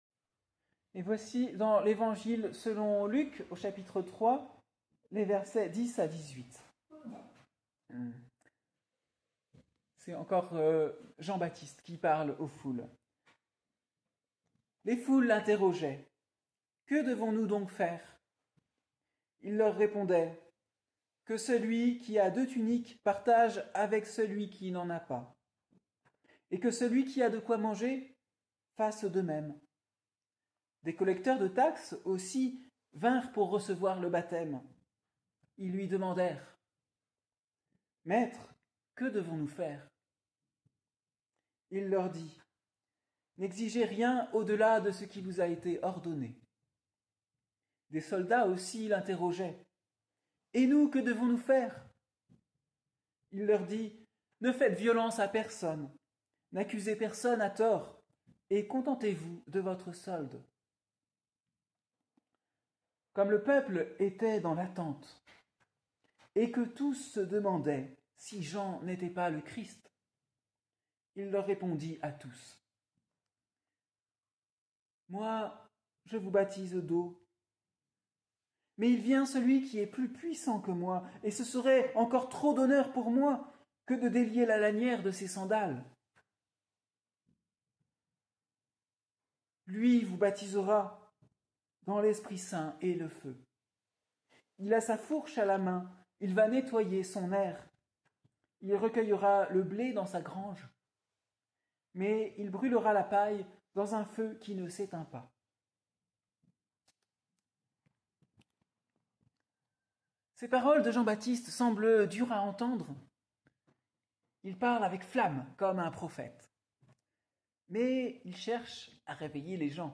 Prédication du dimanche 15 décembre 2024, troisième dimanche de l'Avent